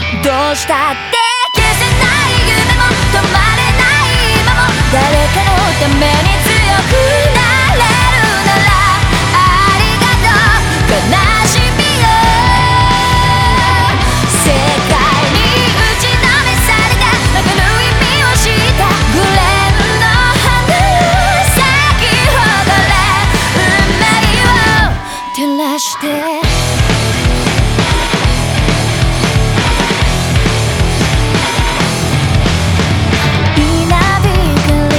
J-Pop
2019-04-21 Жанр: Поп музыка Длительность